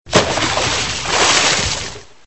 converted sounds to mono
cannons_splash.4.ogg